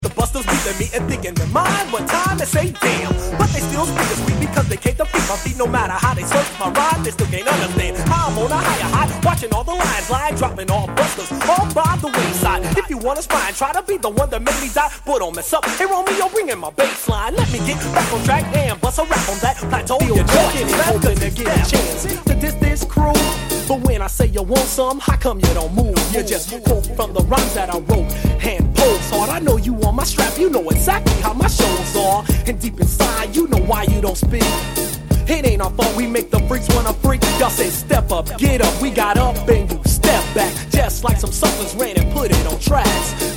of 1988 gangsta rap